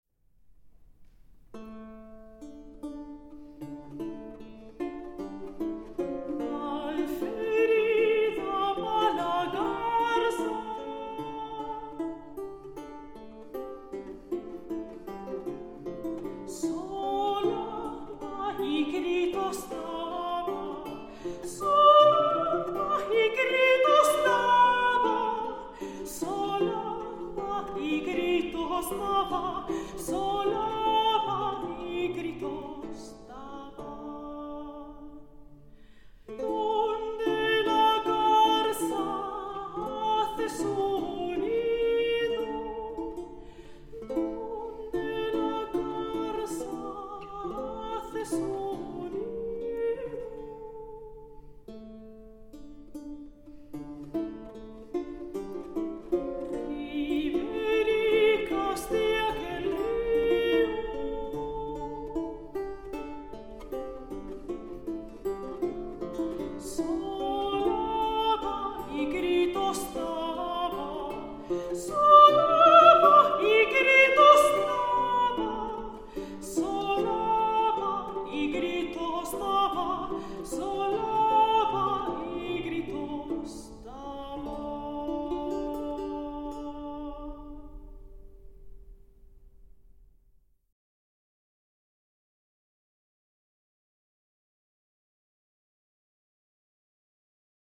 vihuela.mp3